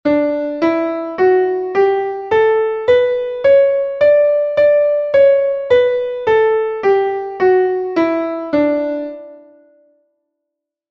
Para practicar, o primeiro que imos facer é coñecer a súa sonoridade a través dos audios que seguen: Escala Maior audio/mpeg audio/mpeg Escala menor audio/mpeg audio/mpeg
escaladreM.mp3